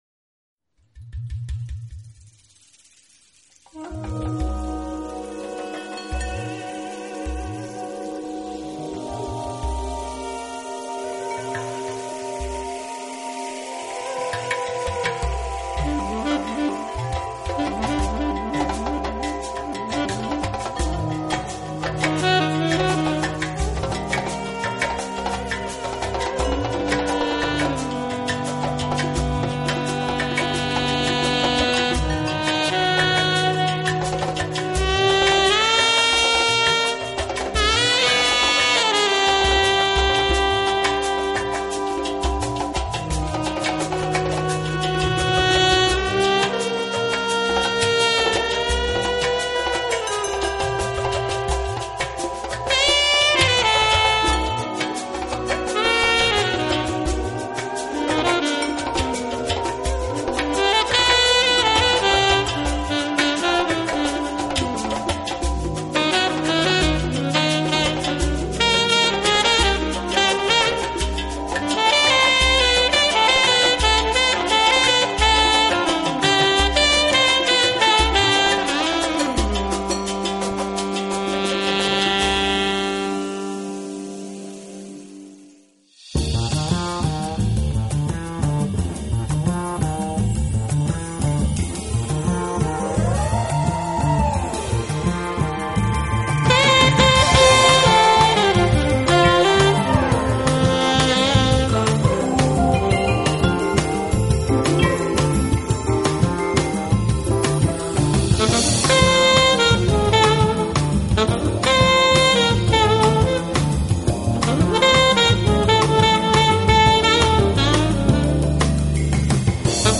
音乐类型：Jazz
而钢琴具Upbeat的节拍，起了一份清爽的调节作用，令到音乐更动听。